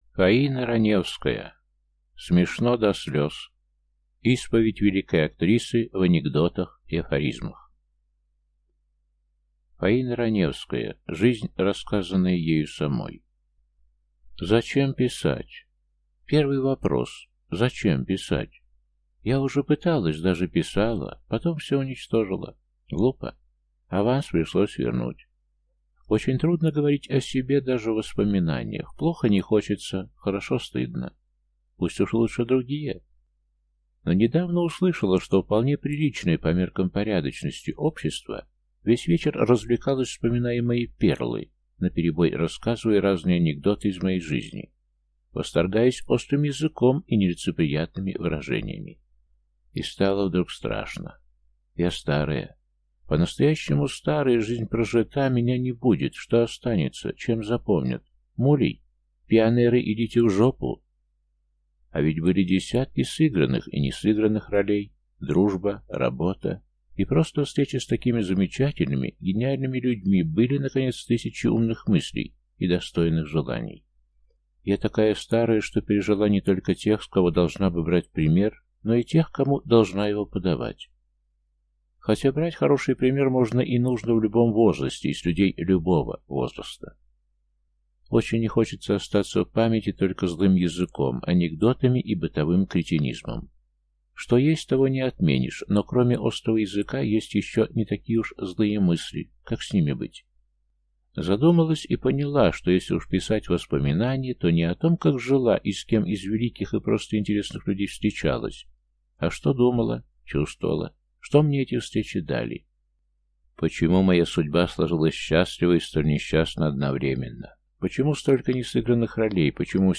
Аудиокнига Смешно до слез. Исповедь великой актрисы в анекдотах и афоризмах | Библиотека аудиокниг